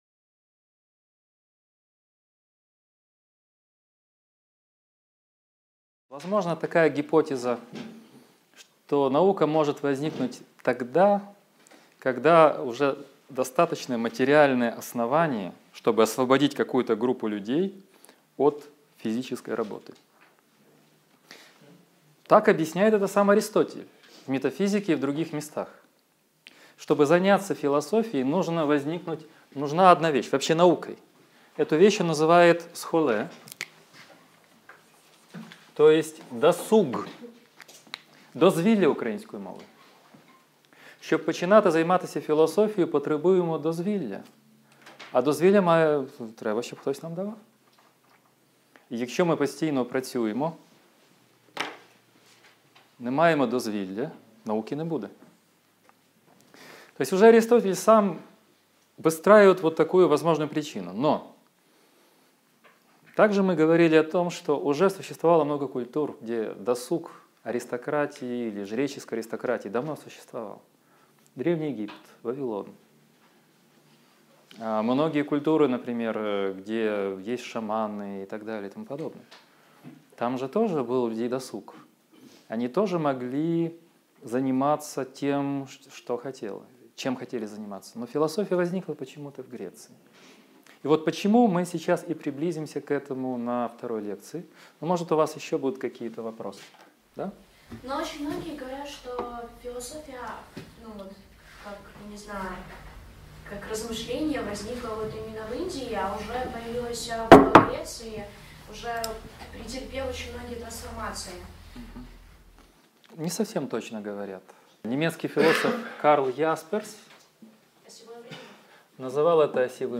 Аудиокнига Лекция 2. Почему возникла философия?